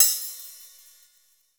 HO RIDE.wav